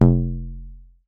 PVC_pipe_hit_2
block bong bonk bottle bumper container drop dropped sound effect free sound royalty free Music